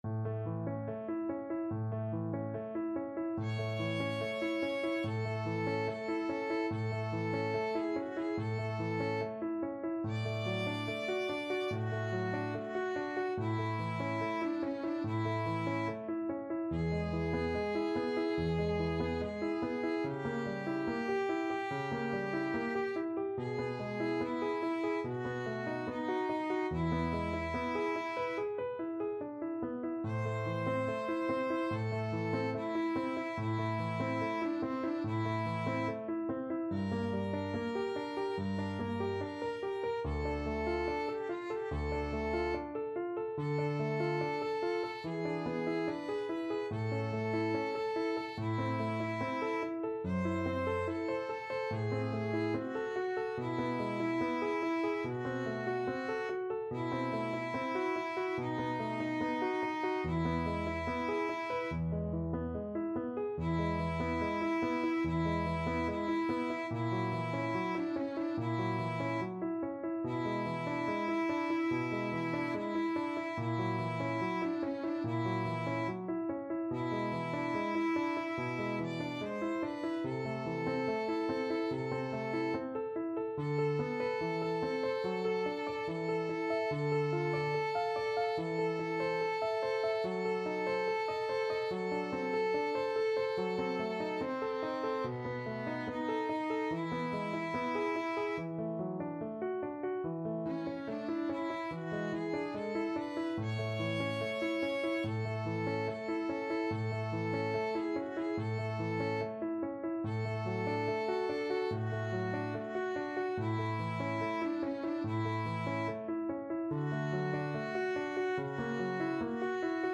Play (or use space bar on your keyboard) Pause Music Playalong - Player 1 Accompaniment reset tempo print settings full screen
A major (Sounding Pitch) (View more A major Music for Violin Duet )
Andante =72
Classical (View more Classical Violin Duet Music)